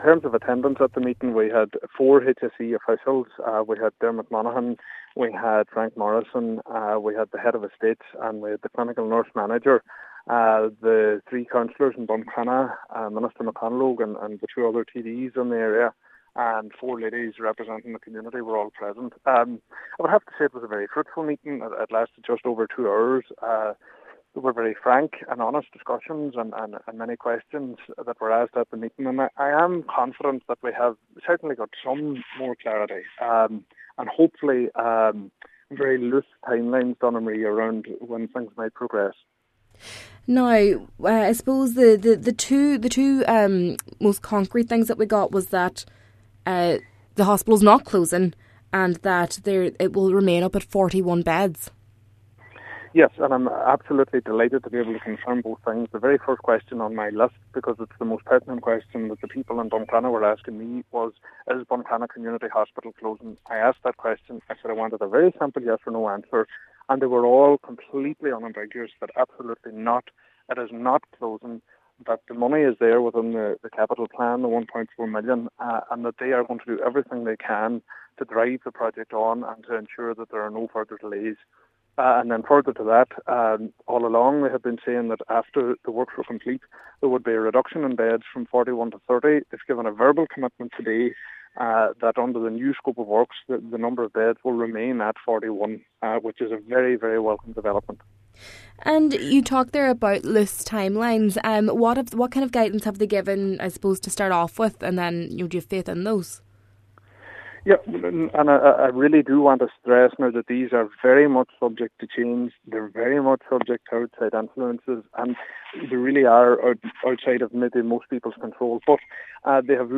Cllr Bradley says a further meeting has also been secured: